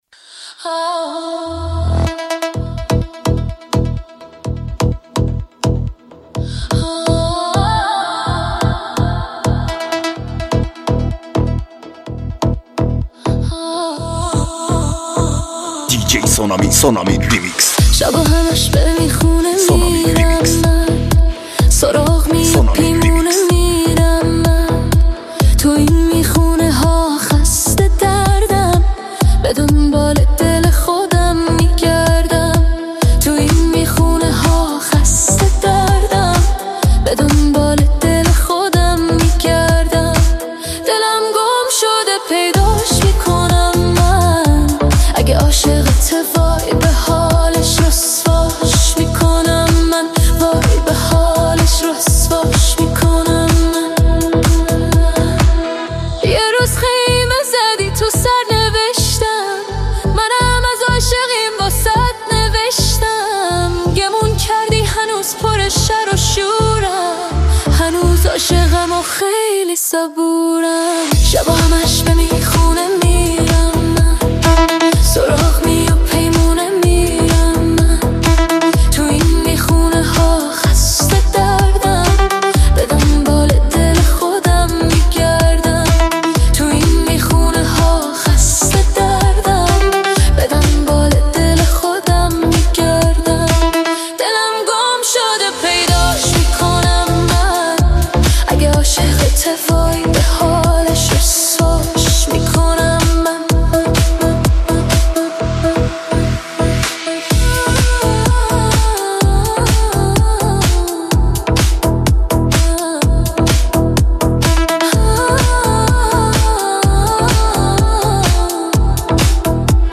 ژانر: ریمیکس